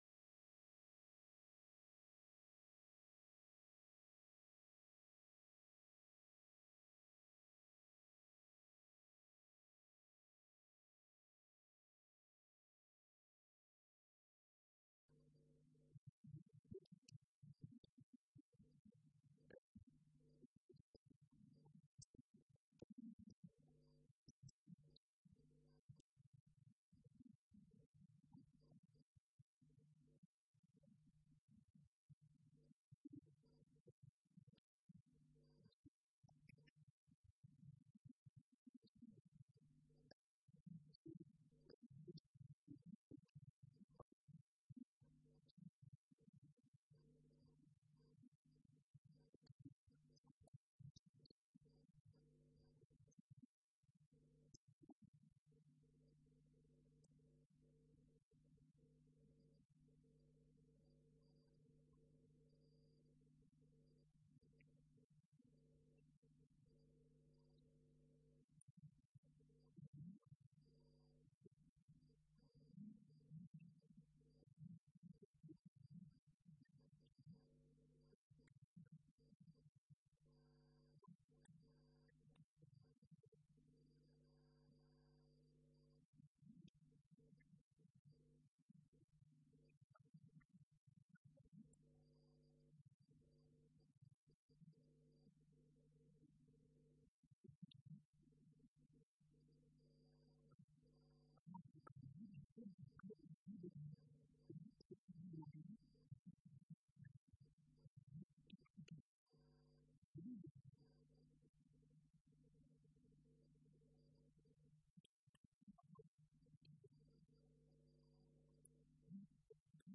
19 Débat : Grammaire ou choc des civilisations ?
Colloque L’Islam et l’Occident à l’époque médiévale.